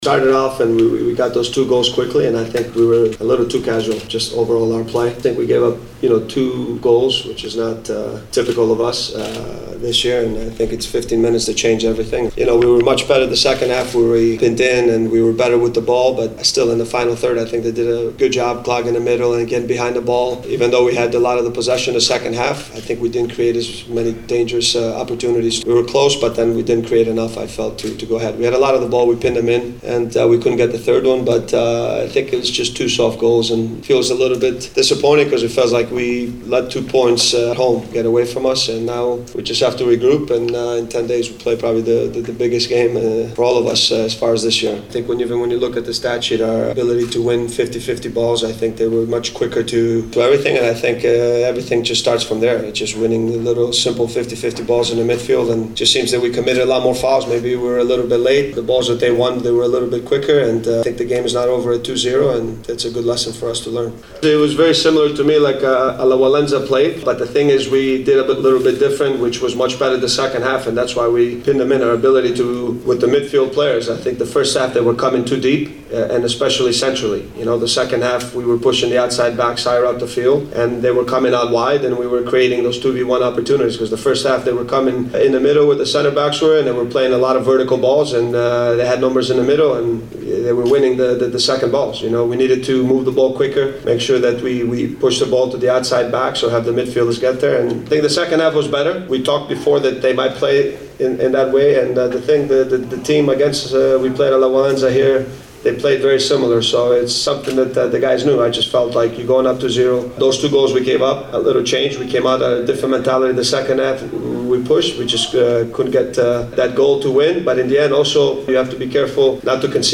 Le interviste: